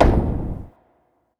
Perc (Sizzle Stomp)(1).wav